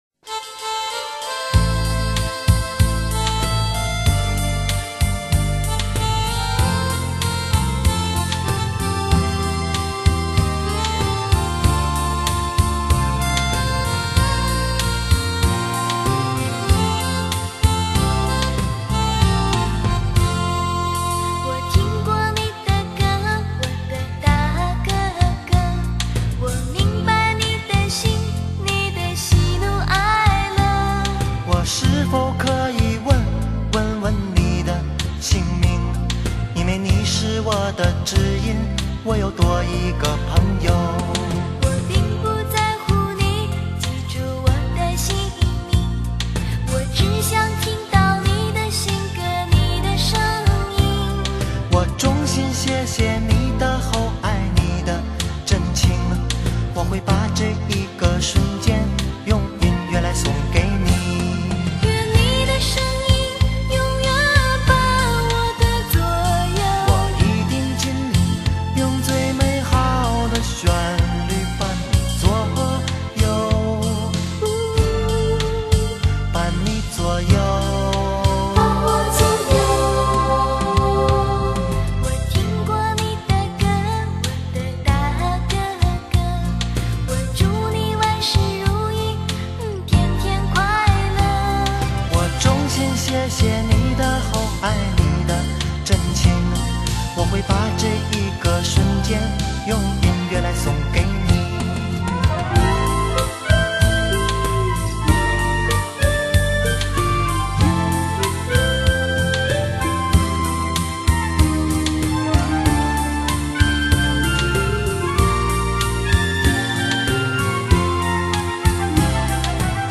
light]经典怀旧情歌